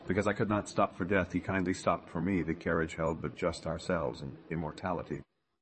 tortoise-tts - (A fork of) a multi-voice TTS system trained with an emphasis on quality